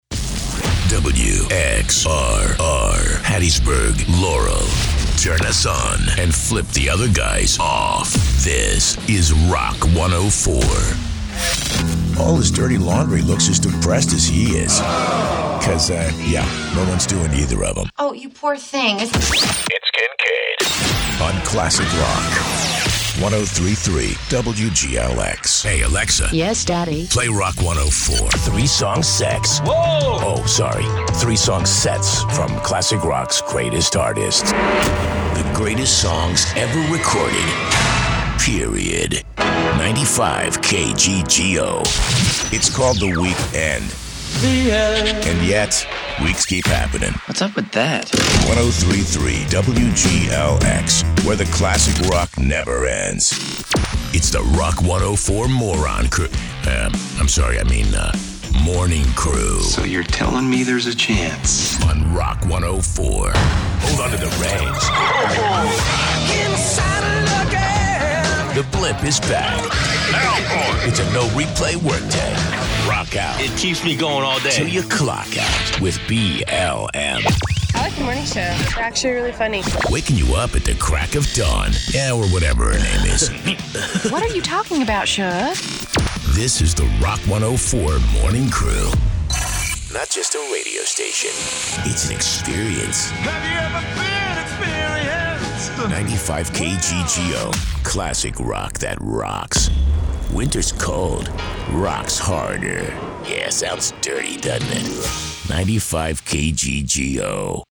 Here’s a few descriptions pulled from his many testimonials…Easy to work with…great attitude…reliable…professional…voice that cuts through…great range…consistent delivery.
Imaging Voice Demos: